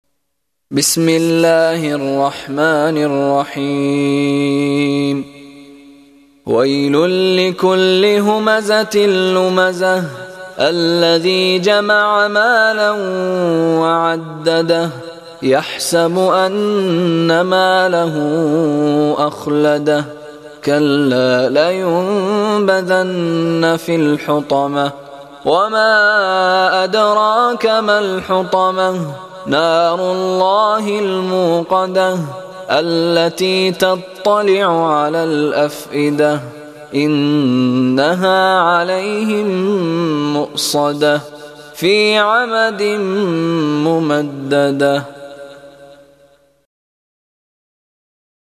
Голос верующего, читающего исламскую молитву на арабском